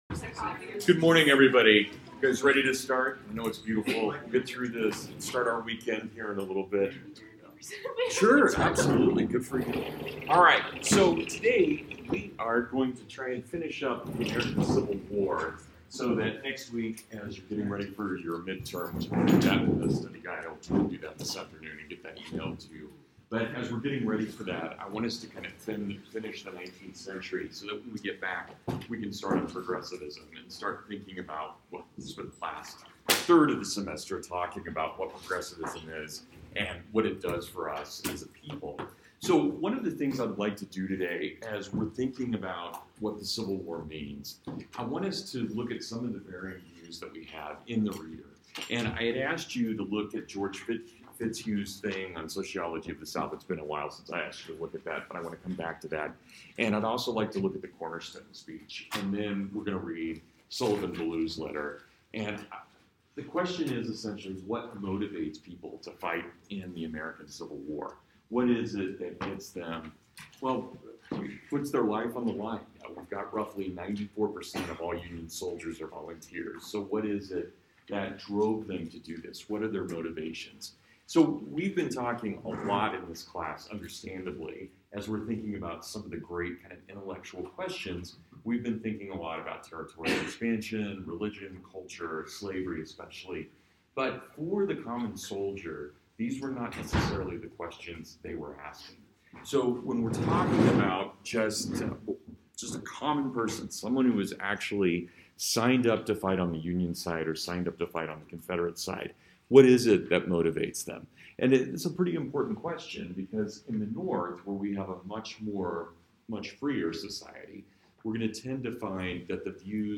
Why Soldiers Fought in the Civil War (Full Lecture)
From the National Archives.